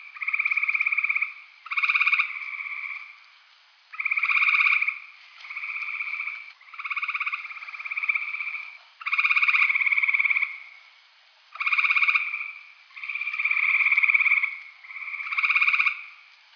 Sympatric Gray Treefrogs in DeWitt County, Texas
I immediately came across one of my target species calling, the Gray Treefrog (Hyla versicolor).
These three frogs were calling from a group of trees probably near a pond off the side of the road.
In this case, the temperature was roughly the same at both locations (~64°F) and so they are directly comparable.
All of the many Gray Treefrogs (Hyla versicolor) I heard that night had pulse frequencies ranging between 16 and 18 pulses per second.